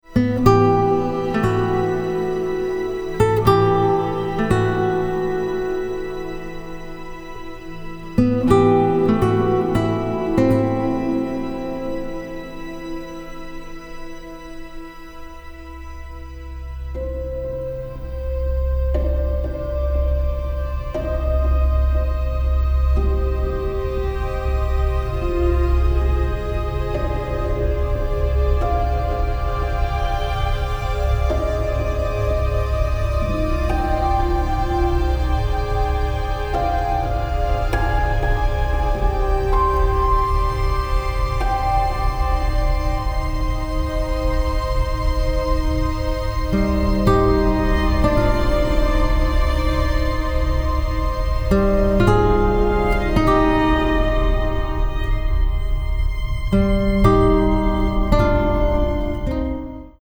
a very emotional and sensitive score
especially cello, harp and guitar.